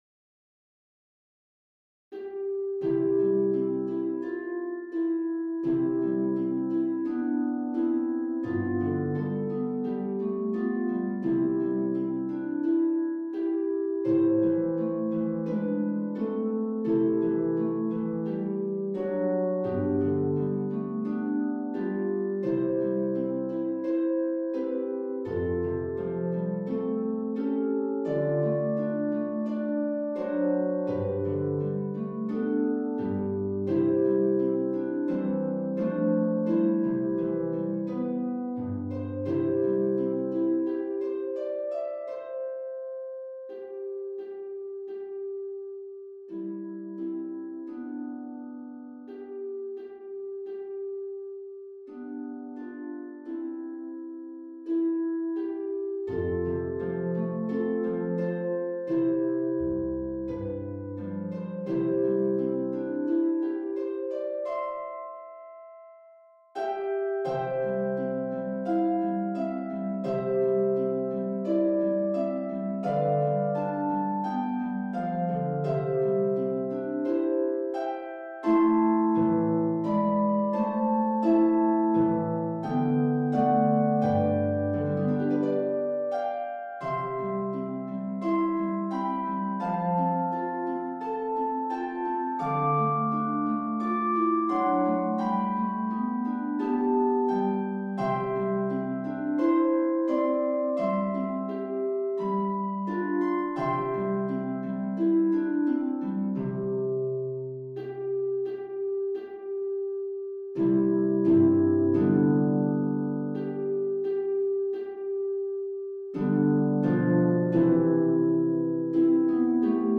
Pedal Harp